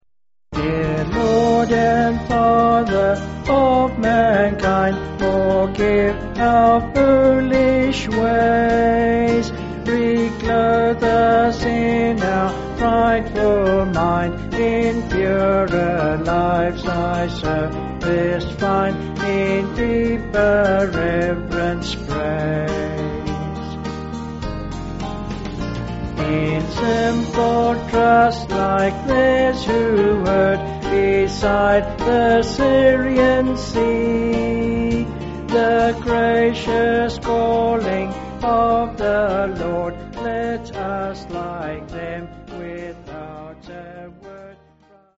(BH)   5/Eb
Vocals and Band